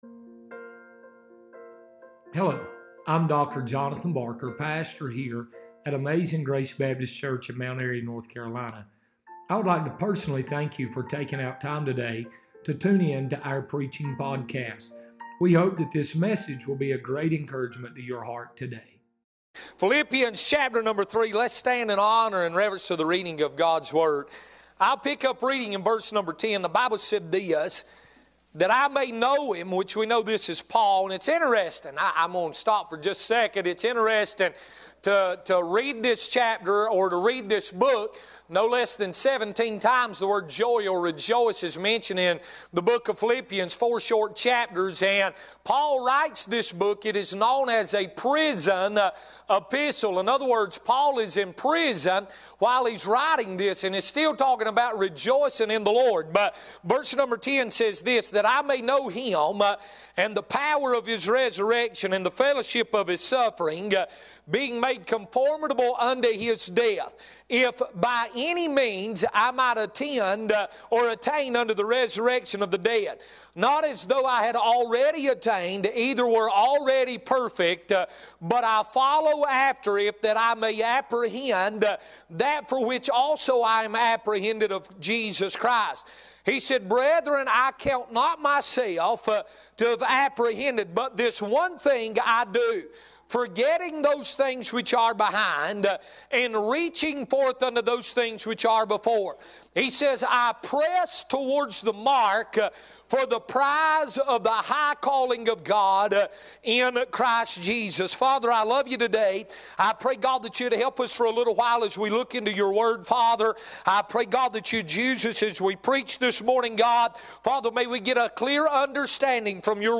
Sermons | Amazing Grace Baptist Church